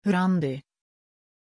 Aussprache von Randy
Schwedisch Spanisch Türkisch
pronunciation-randy-sv.mp3